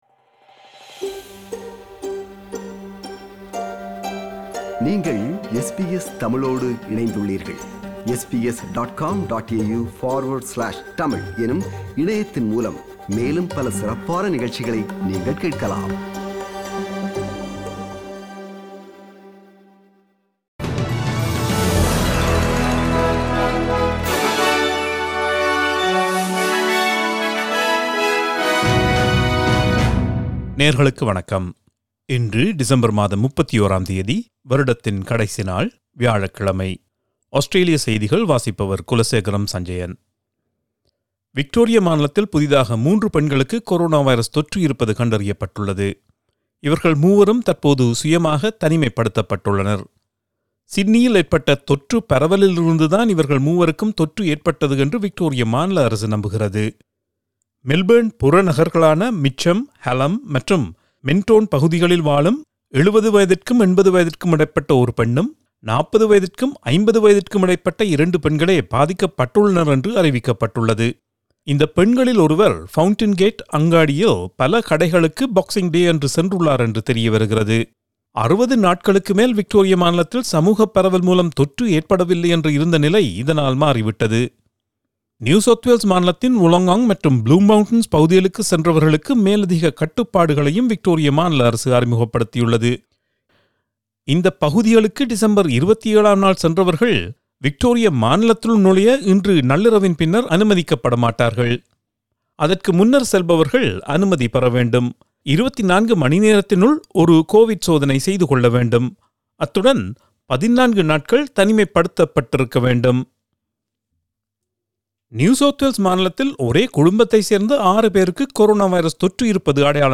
Australian news bulletin for Thursday 31 December 2020.